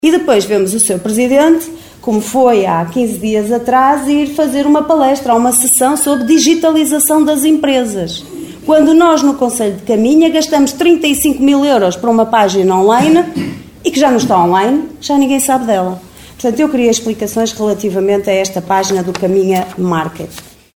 O Caminha Market, plataforma de comércio eletrónico lançada em 2021 em parceria com a Associação Empresarial de Viana do Castelo (AEDVC), na qual a Câmara de Caminha gastou mais de 35 mil euros, está constantemente em baixo, alertou Liliana Silva da Coligação O Concelho em Primeiro (OCP) na última reunião camarária de 5 de março.